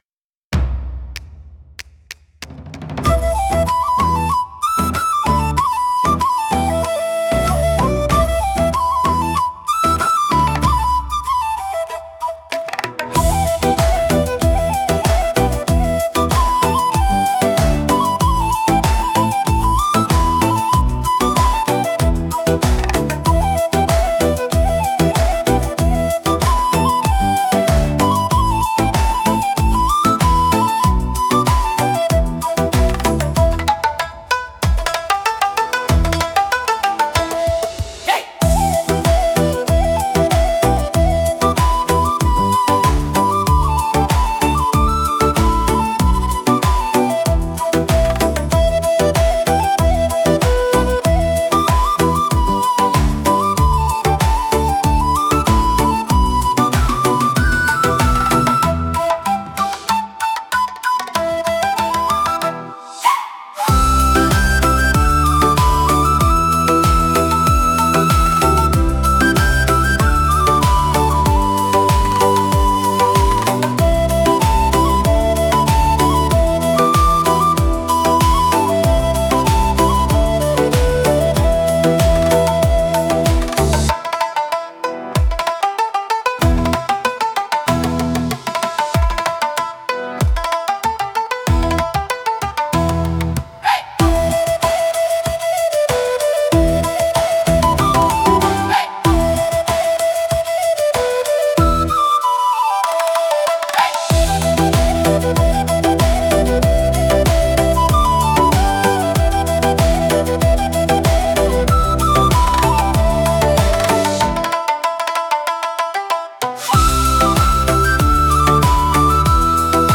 出てきたPrompt : traditional okinawan folk, kachashi dance
ただし、何もメロディーの音色はパンフルートか尺八のようなものと琴のようなもので、「三線」は理解できなかったようだ。 2パターン作ってくれたもののビート感なりコード感は同一で、これでは沖縄の国際通りのBGMとしても失格という感じである。